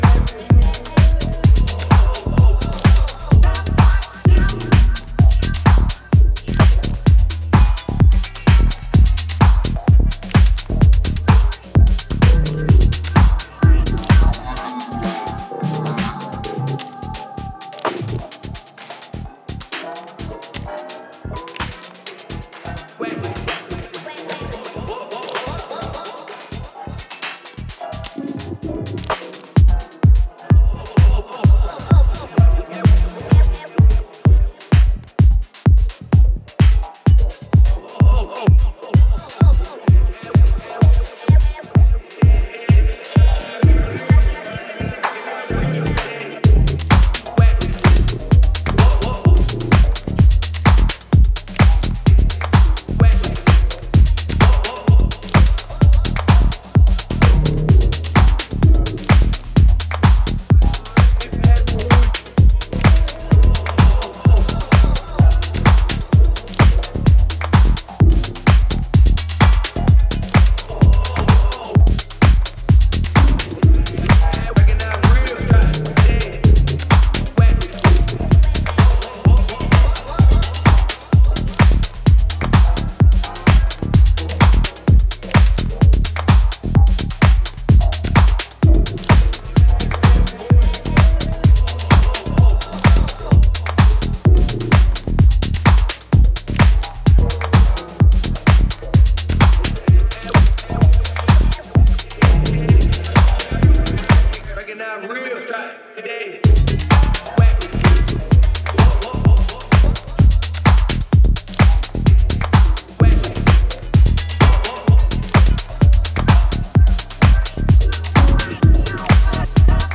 broken take on minimal
deep remix